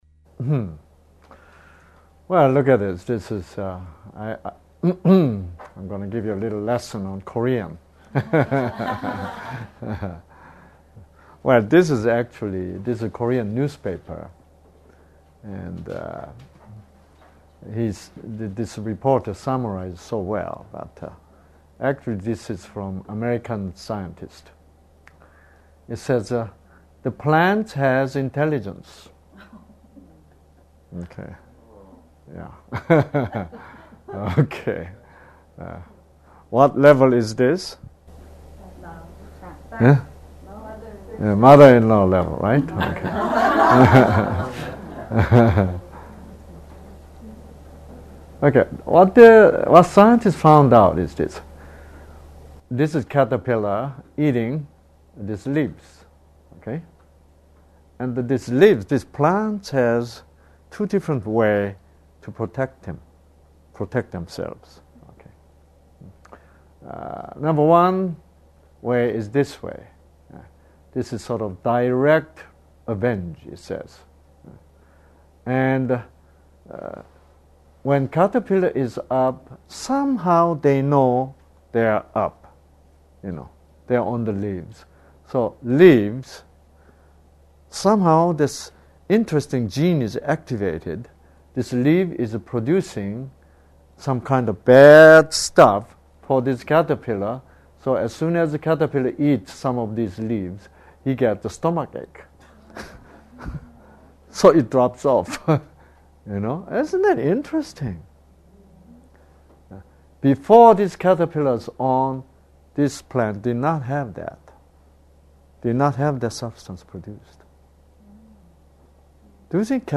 세미나 동영상 - [English] Arizona Seminar Lectures, USA